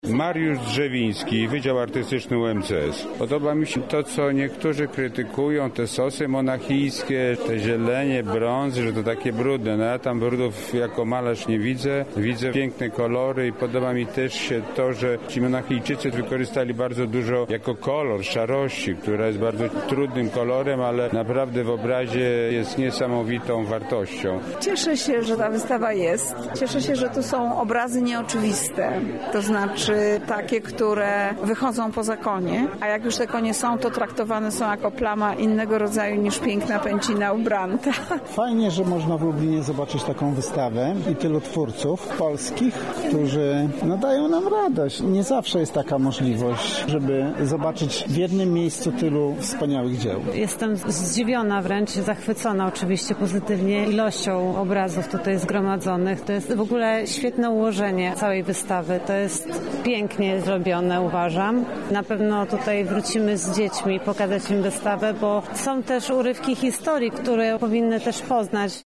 Za nami wernisaż wystawy. Dowiedzieliśmy się, jakie emocje panowały podczas tego wyjątkowego wydarzenia:
Relacja, „Monachijczycy”